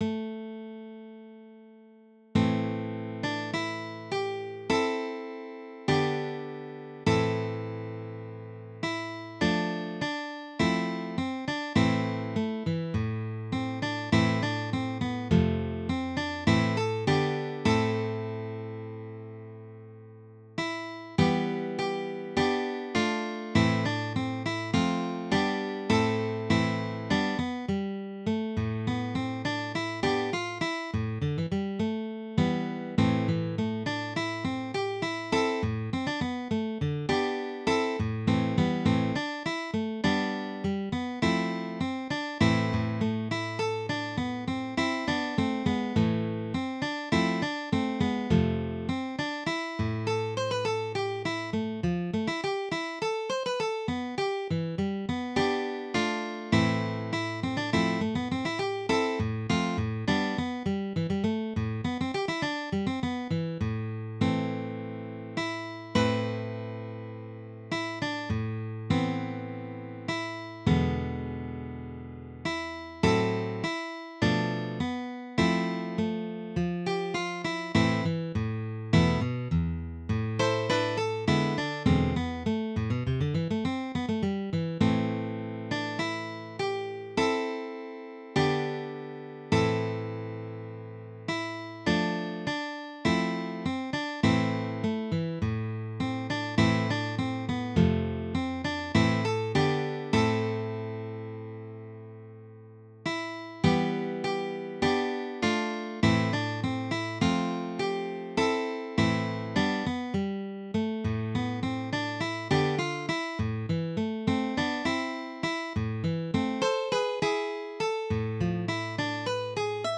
DIGITAL SHEET MUSIC - FLATPICK/PLECTRUM GUITAR SOLO
Sacred Music
Dropped D tuning